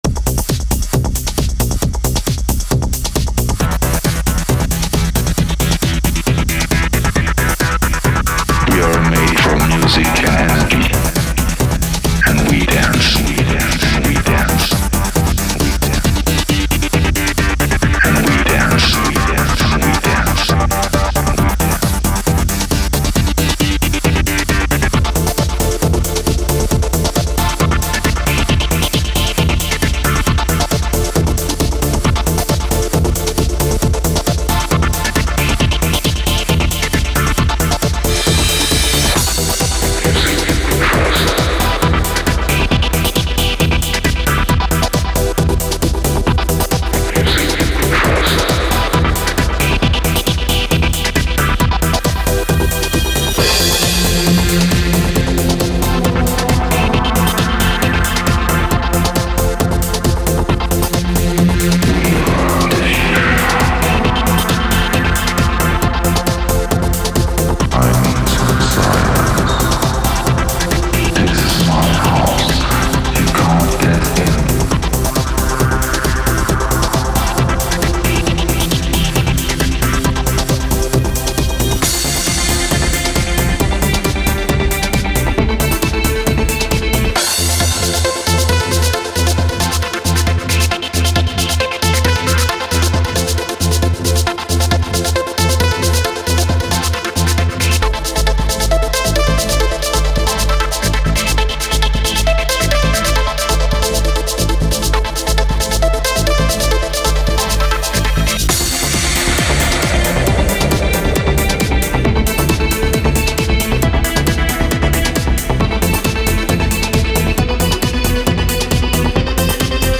Techno χορευτική διάθεση